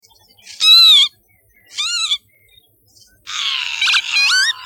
Vanneau huppé en vol
Effrayé, le vanneau huppé prend son envol en poussant de petits cris perçants.
vanneau.mp3